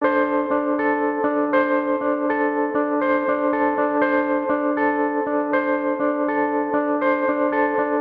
贝斯 吉他 风琴 男声 爵士乐
声道立体声